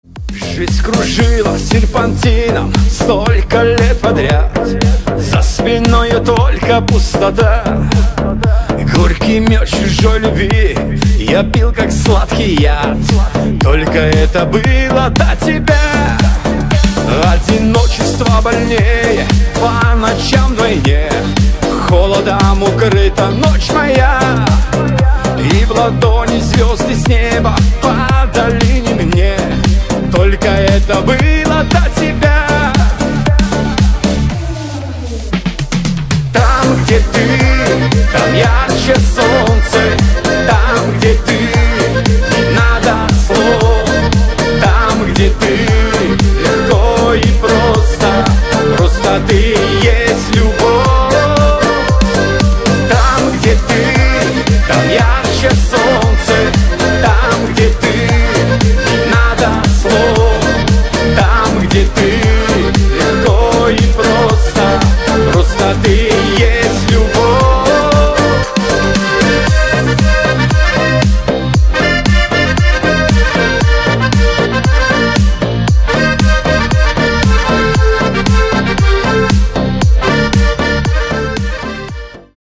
• Качество: 320, Stereo
мужской вокал
аккордеон
русский шансон